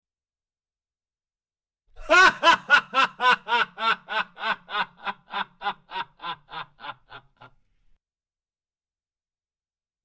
Man Laughing 02
Man_laughing_02.mp3